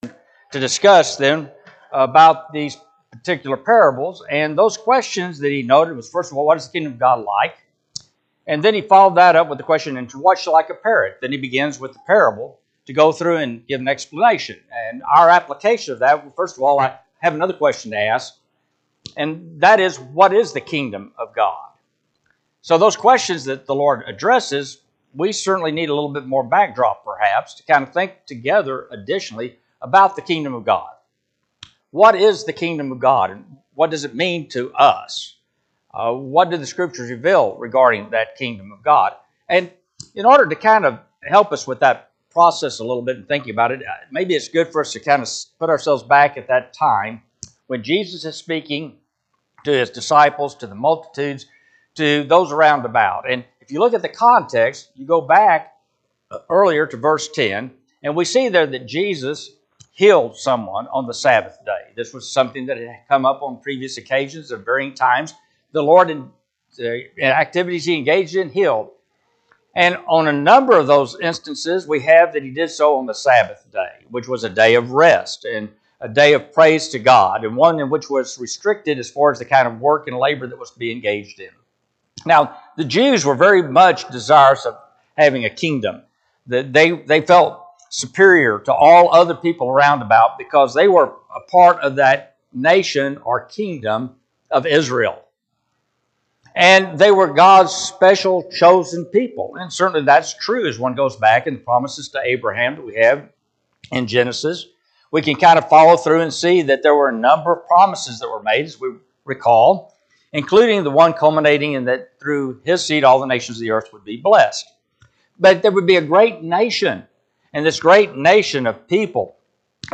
Luke 13:17-21 Service Type: Sunday AM Topics